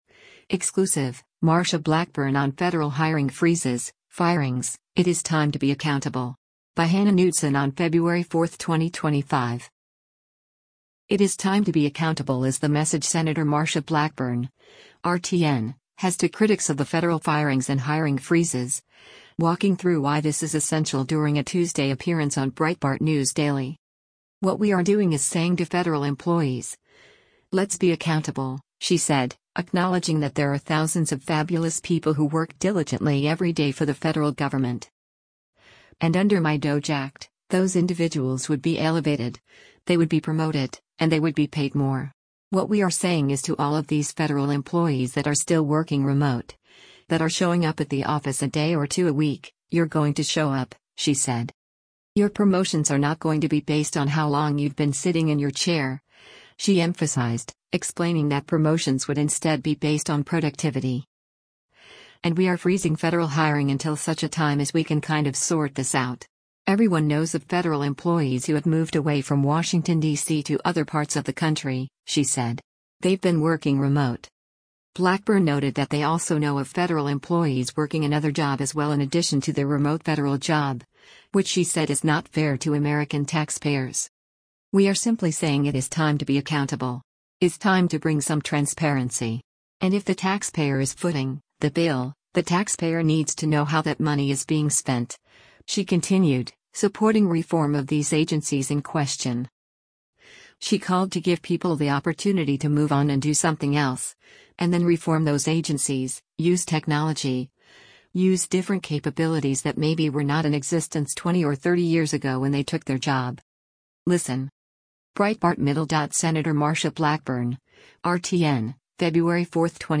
“It is time to be accountable” is the message Sen. Marsha Blackburn (R-TN) has to critics of the federal firings and hiring freezes, walking through why this is essential during a Tuesday appearance on Breitbart News Daily.
Breitbart News Daily airs on SiriusXM Patriot 125 from 6:00 a.m. to 9:00 a.m. Eastern.